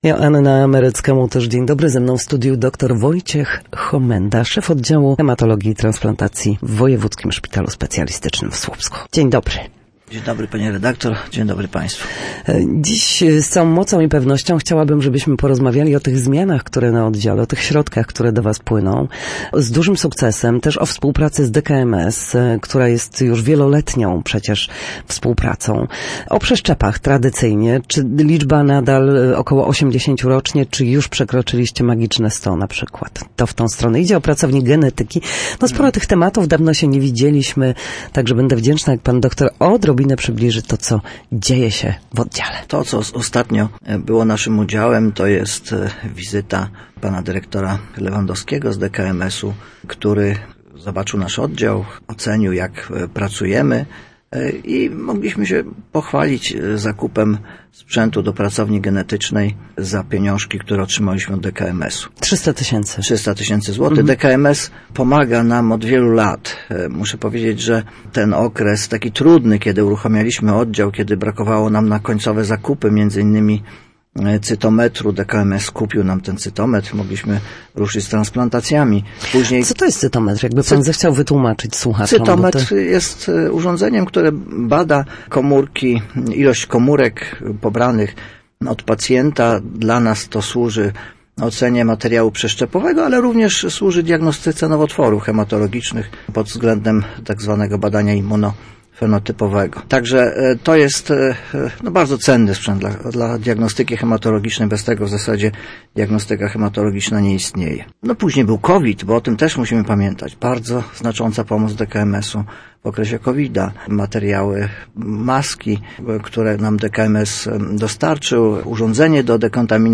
W audycji Na Zdrowie nasi goście, lekarze i fizjoterapeuci, odpowiadają na pytania dotyczące najczęstszych dolegliwości, podpowiadają, jak wyleczyć się w